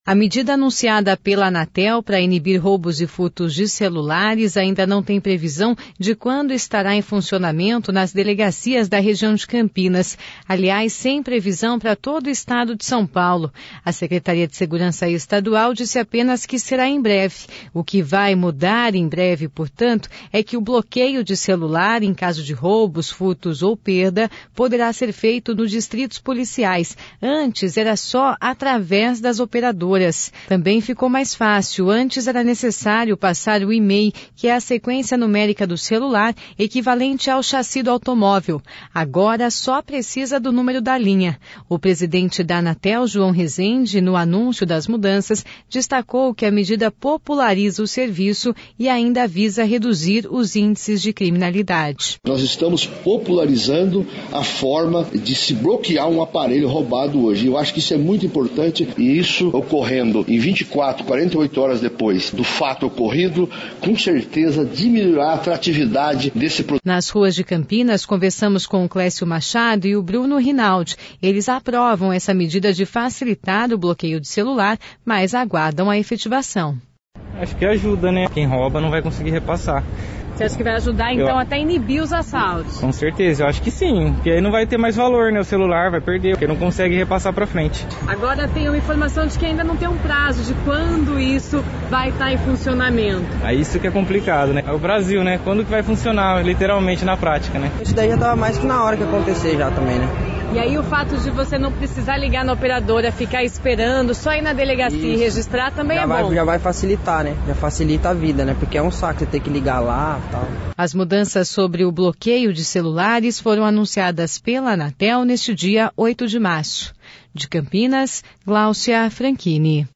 O presidente da Anatel, João Rezende, no anúncio das mudanças, destacou que a medida populariza o serviço e ainda visa reduzir índices de criminalidade.